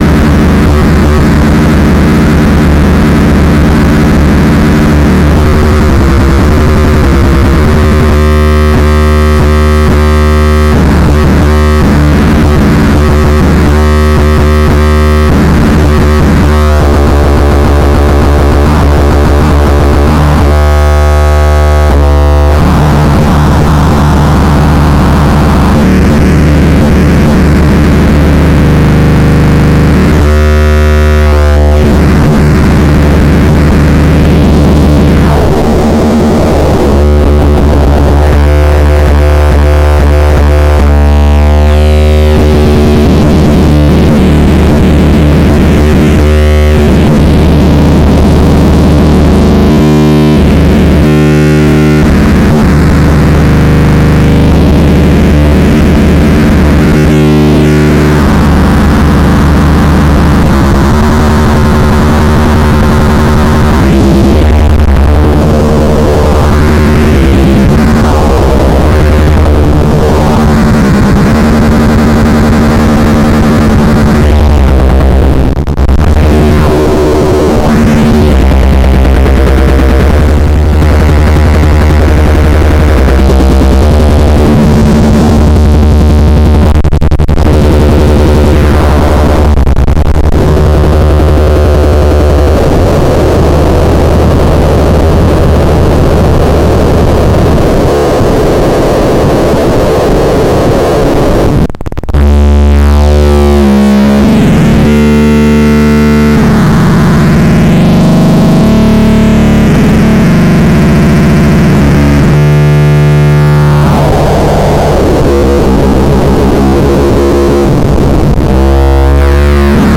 Just Lyra, no drive, no delay.